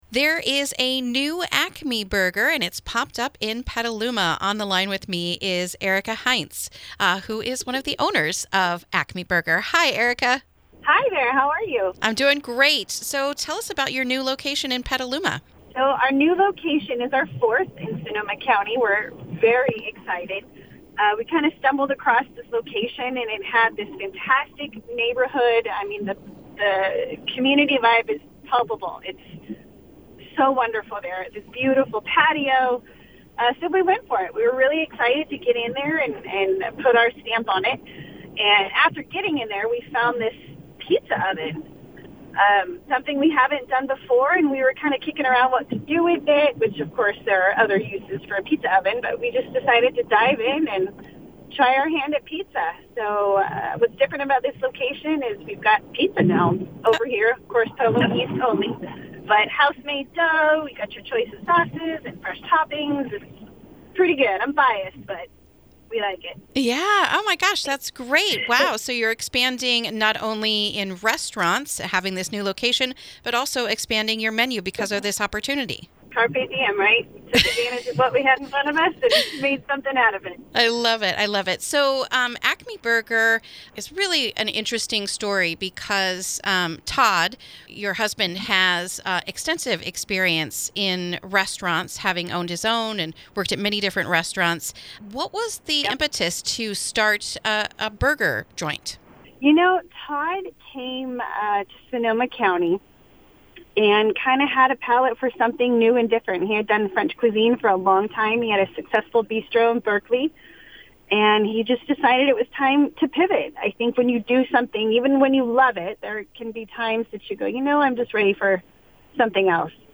INTERVIEW: Acme Burger Opens a New Location in East Petaluma